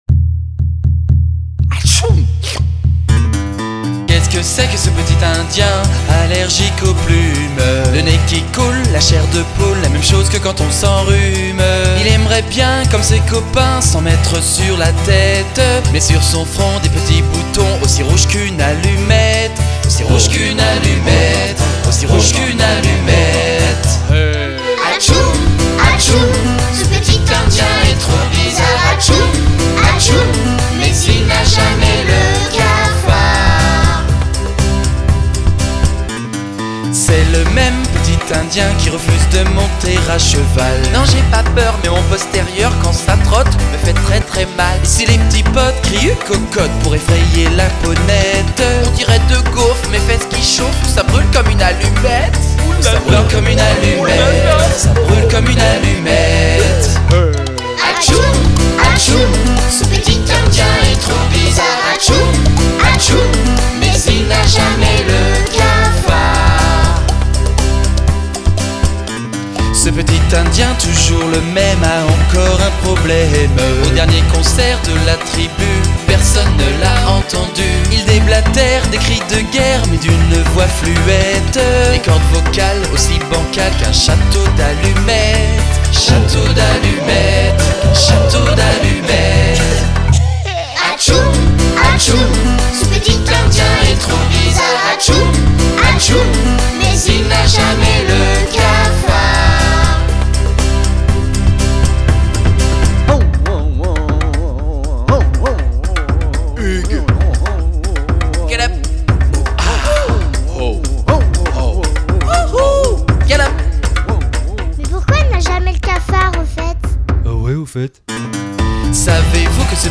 Une nouvelle chanson pour des enfants (je dirais pour les 5-7 ans). C’est assez rapide et rythmé et il y a pas mal de texte à débiter.